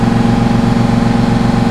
MOTOR13.WAV